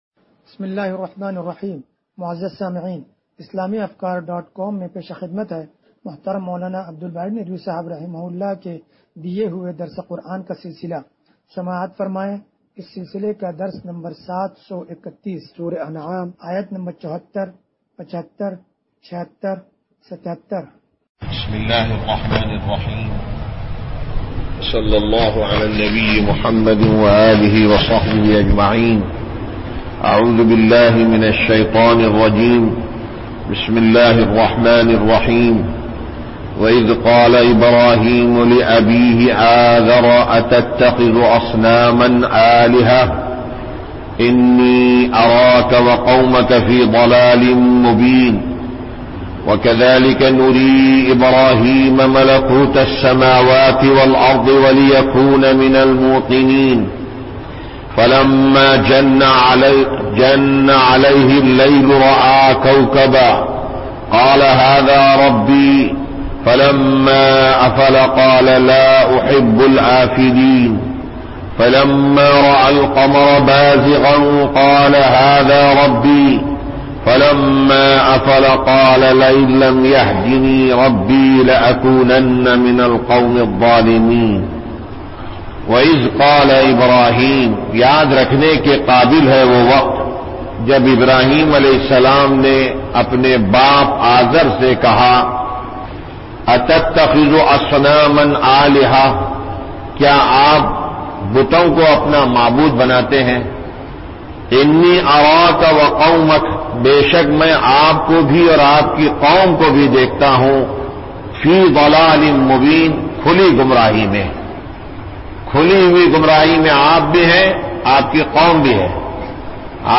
درس قرآن نمبر 0731
درس-قرآن-نمبر-0731.mp3